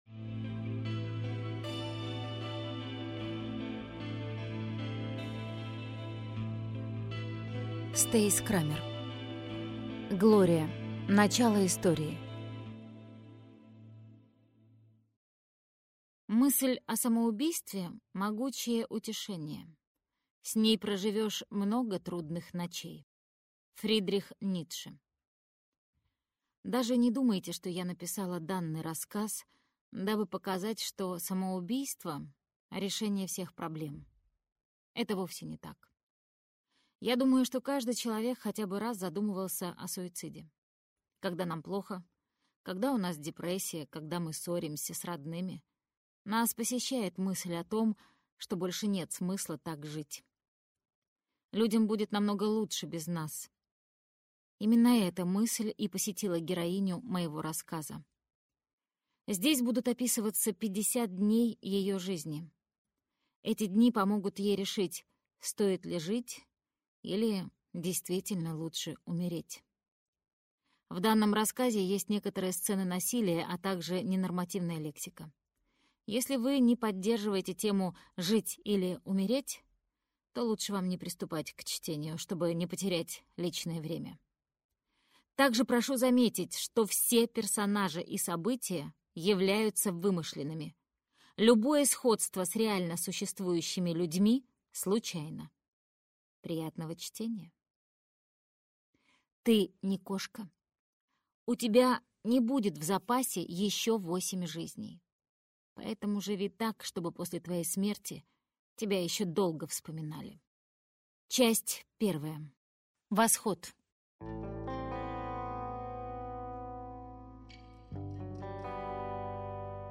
Аудиокнига Глория. Начало истории - купить, скачать и слушать онлайн | КнигоПоиск